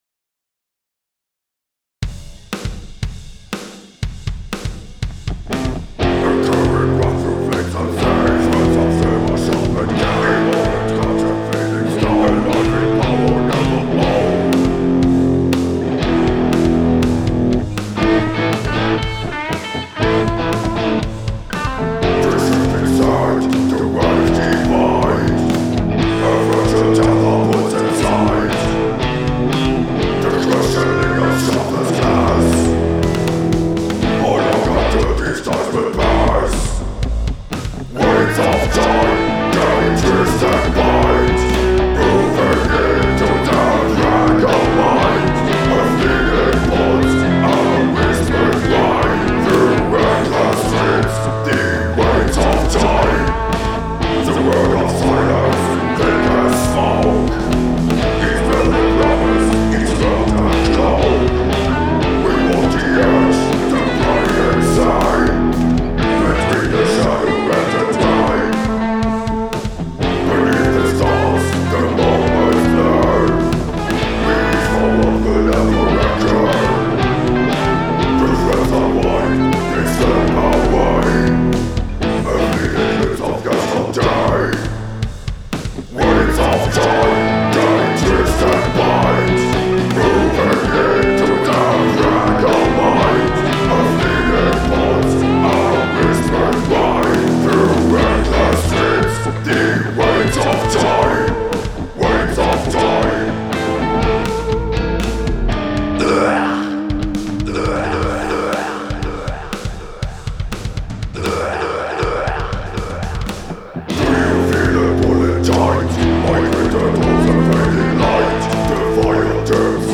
Dem Kollegen mit dem Blaswandler wurde der Job als Bass zugewiesen.